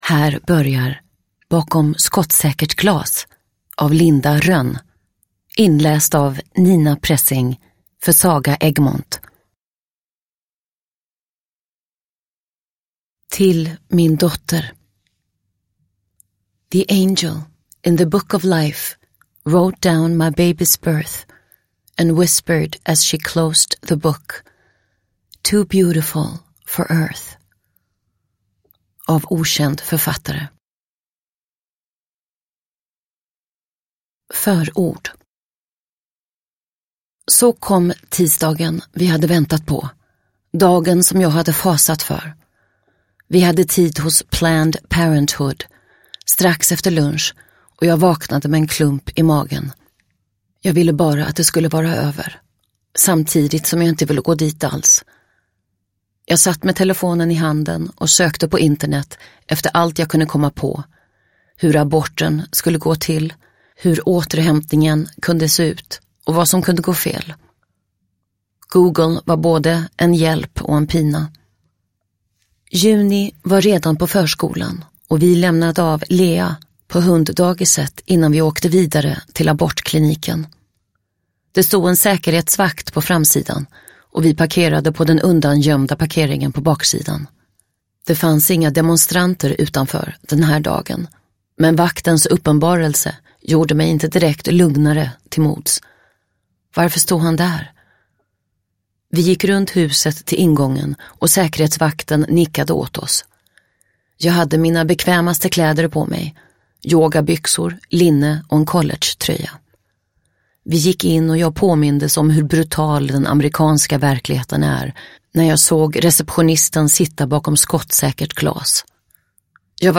Bakom skottsäkert glas – Ljudbok – Laddas ner